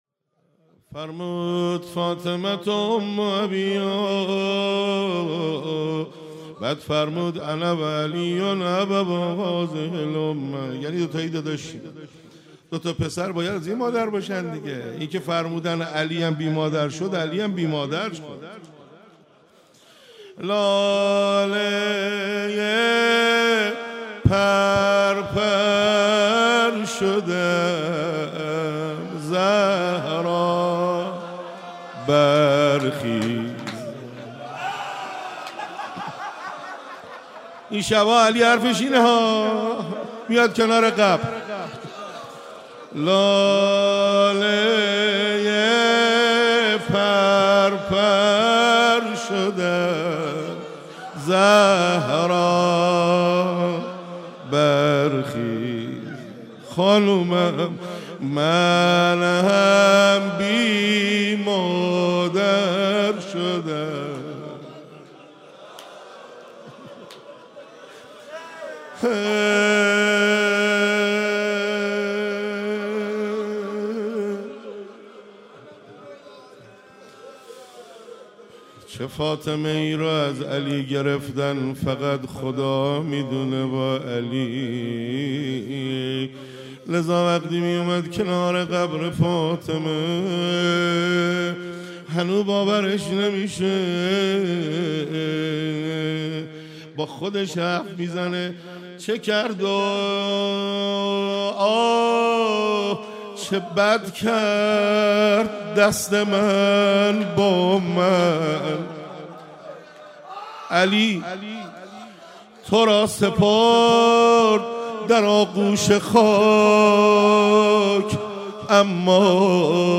فاطمیه 96 - روضه - لاله پر پر شده ام زهرا